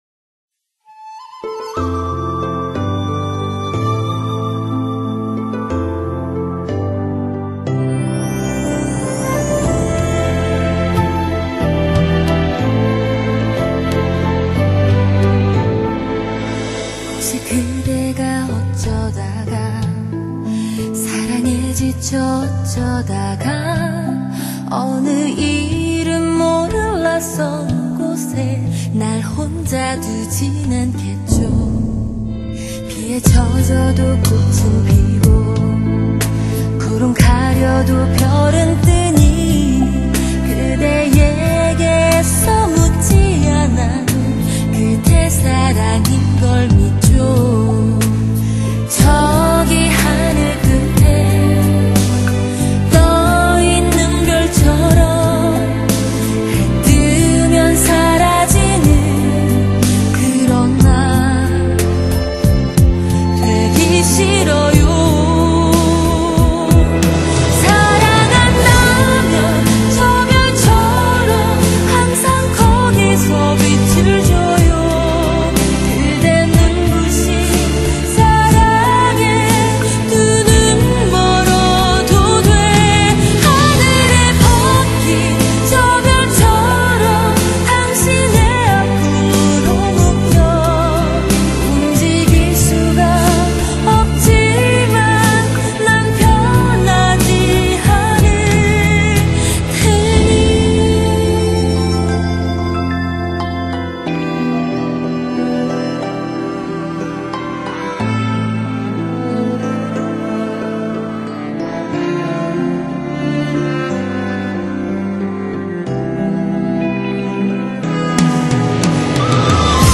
这首歌，很好听，但没有高音质！